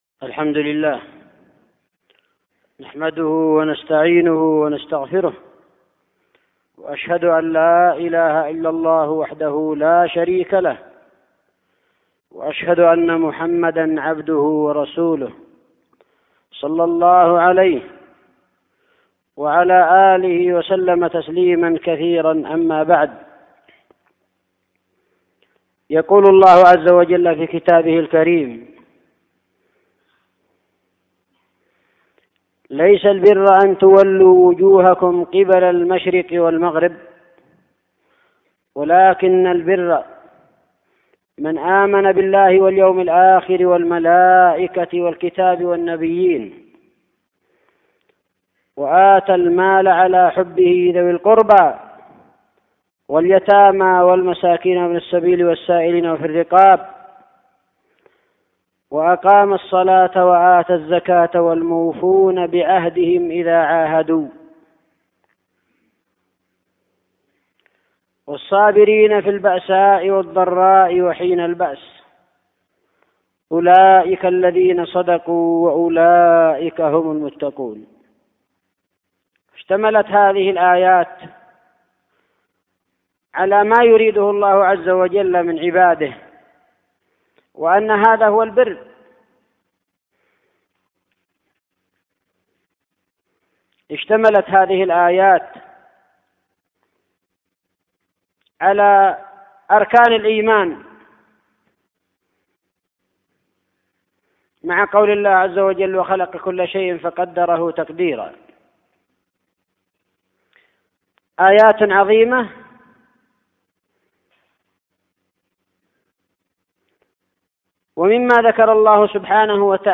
خطبة جمعة بعنوان : (( وجوب الوفاء بالعهود والمواثيق الملتزمة ))